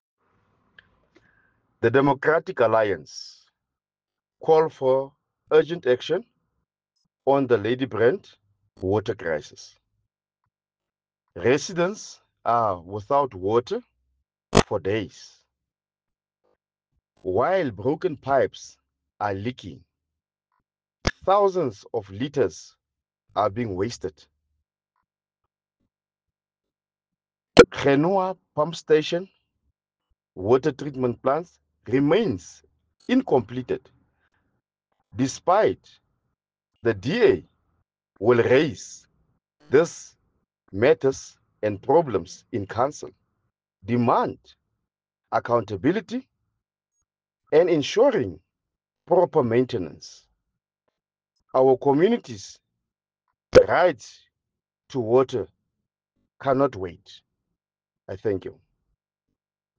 Sesotho soundbites by Cllr Nicky van Wyk.